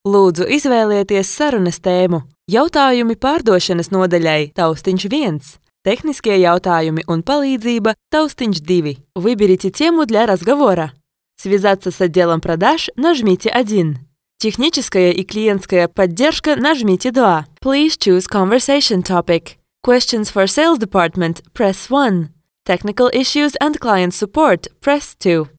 Professional Latvian Voice Over Artist | Native Latvian Voice Talent
Voice Over Artistes- LATVIAN